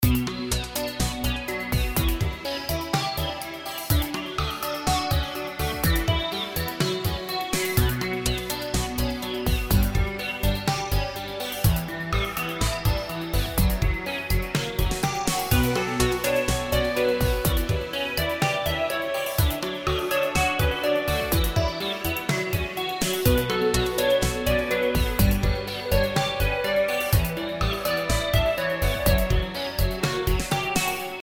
Оч даже музыка звезд, если доделать конечно.
Вопрос в том, что это Fruity Loops.